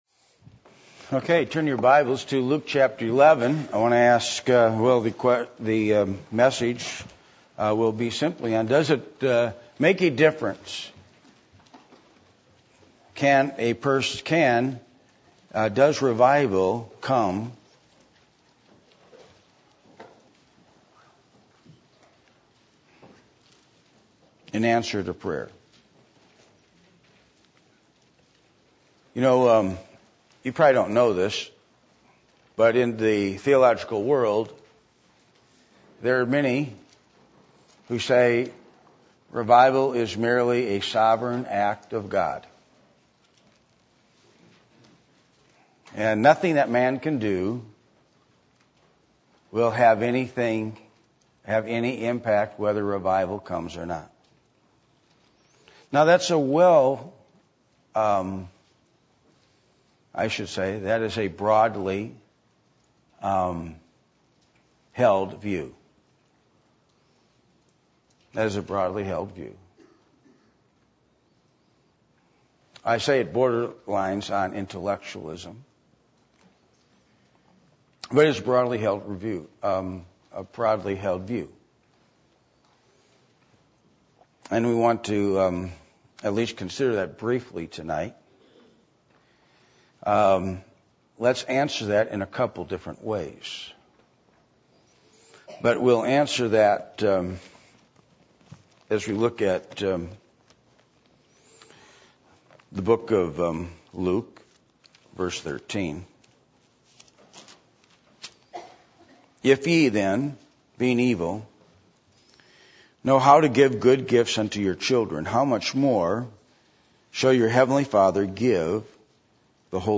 Luke 11:13 Service Type: Midweek Meeting %todo_render% « Increasing The Spiritual Power What Does The Name Of Jesus Mean To You?